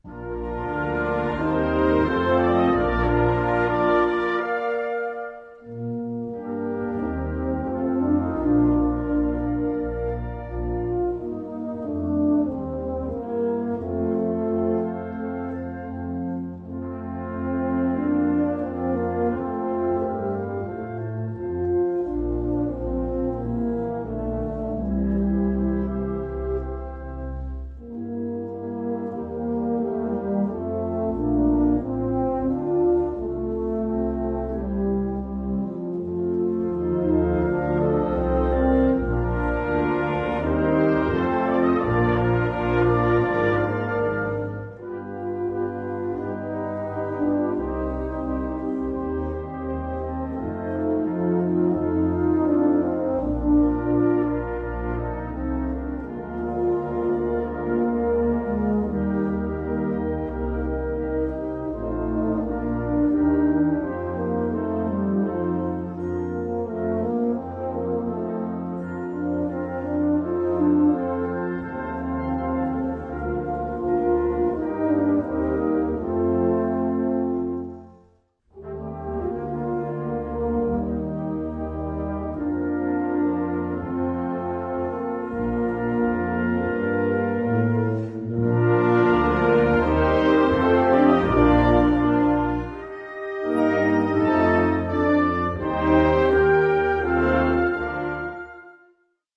Gattung: Arie
Besetzung: Blasorchester